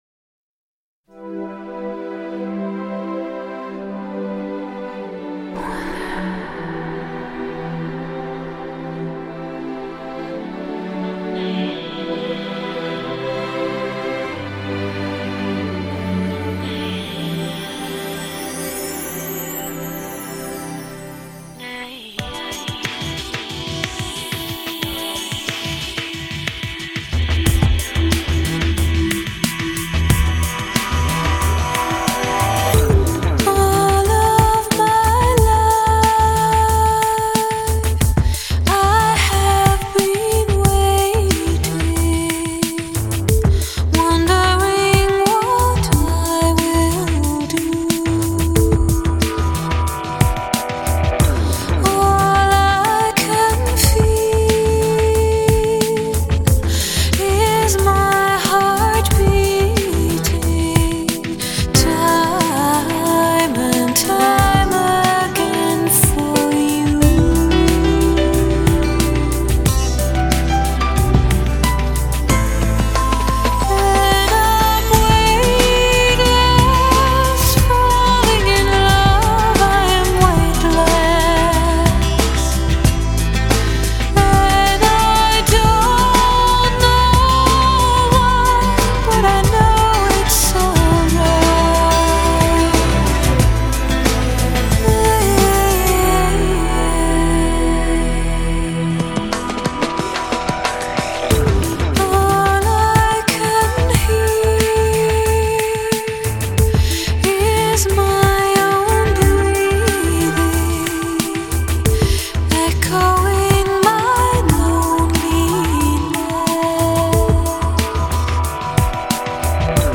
轻柔唱吟 空灵清澈